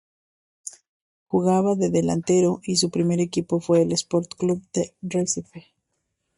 Read more Noun Adv Pron Frequency B2 Pronounced as (IPA) /ˈdo/ Etymology Borrowed from Italian do In summary Borrowed from Italian do.